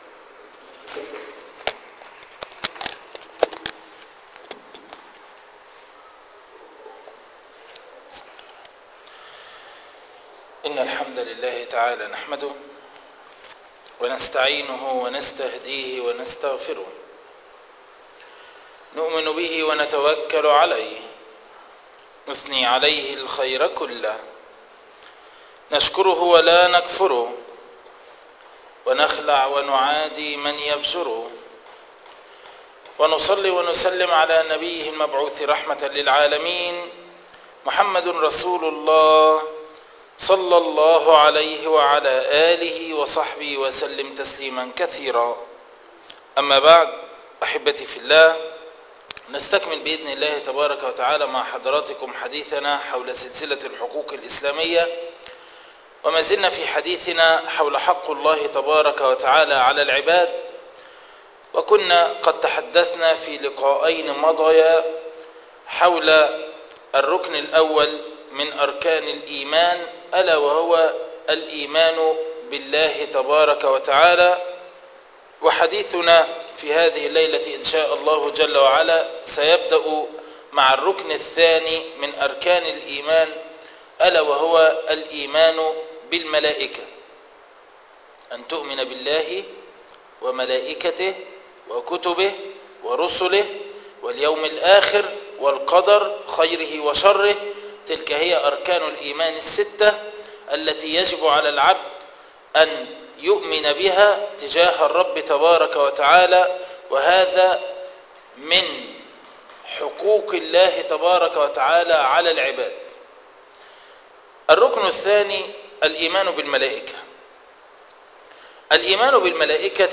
حق الله على العباد الدرس الثانى عشر